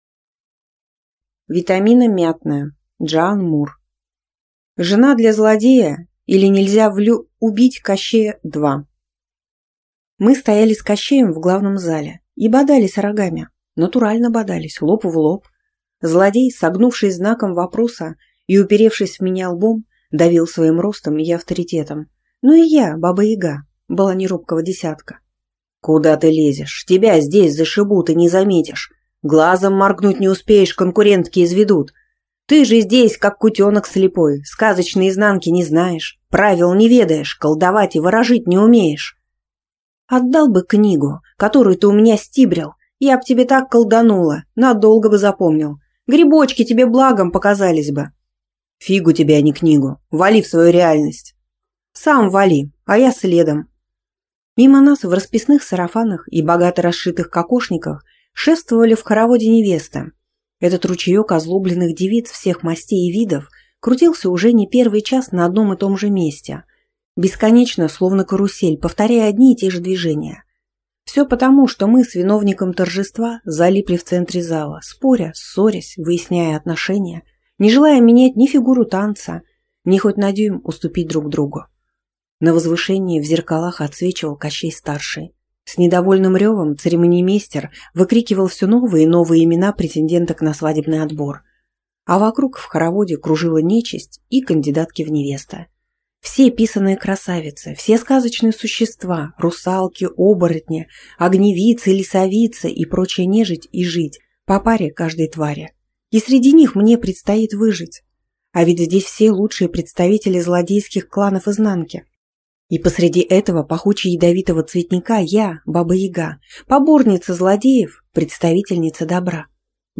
Аудиокнига Жена для злодея, или Нельзя (влю)убить Кощея | Библиотека аудиокниг